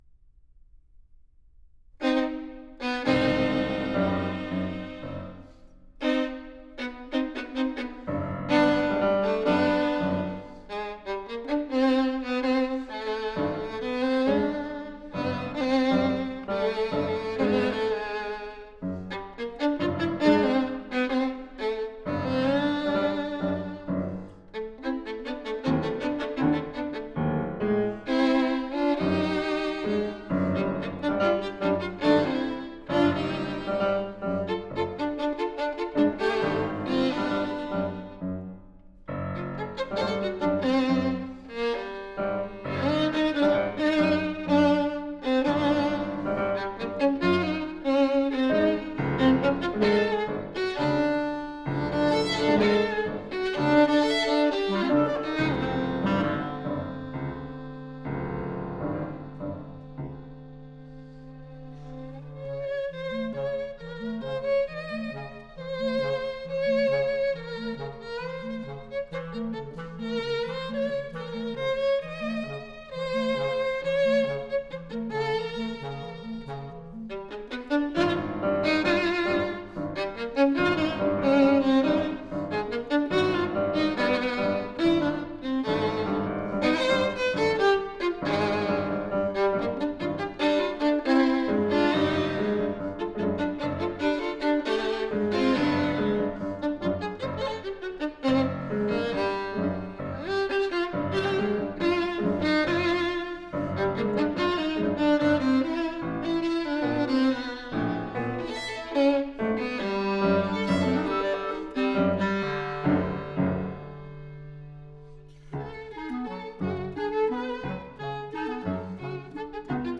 Recorded at the Shalin Liu Performance Center, Rockport, MA